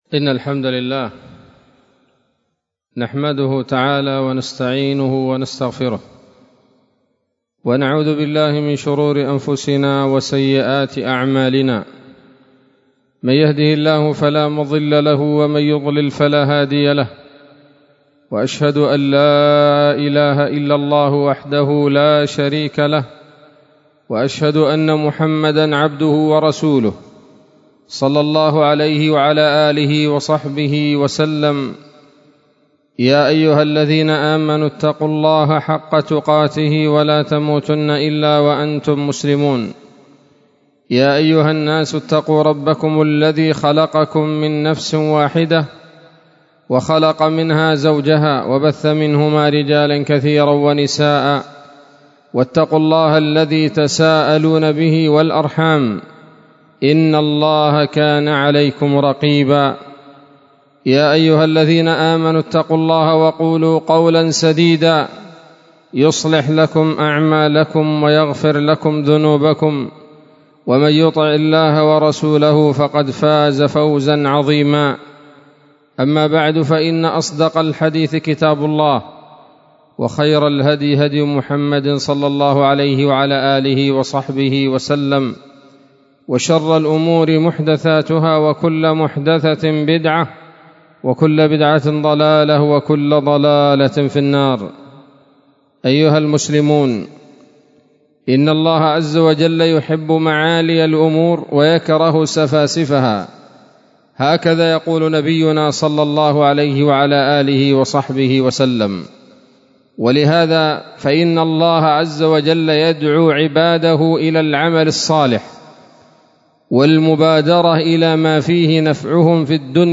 خطبة جمعة بعنوان: (( إياكم والكسل )) 26 شوال 1443 هـ، دار الحديث السلفية بصلاح الدين